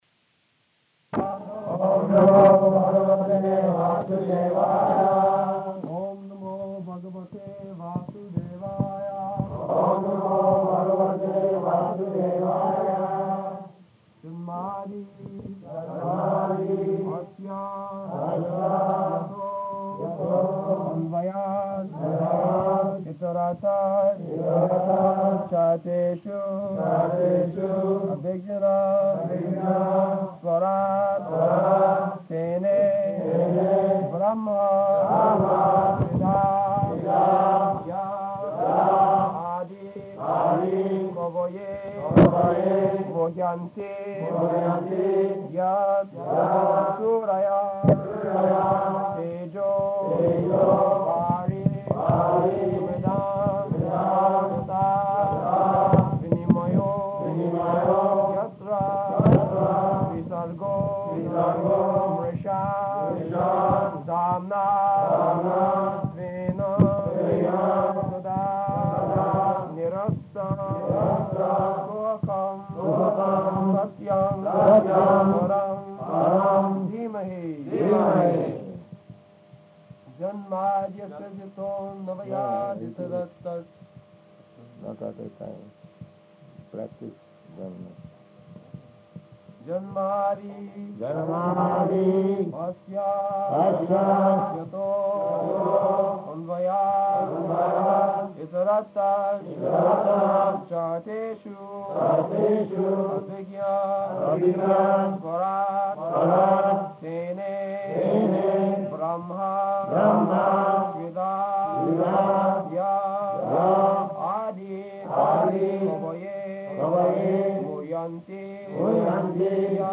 [Prabhupāda and devotees repeat]
[devotees chant, with Prabhupāda correcting] Any girls? [lady devotee chants with Prabhupāda correcting] Any other girl?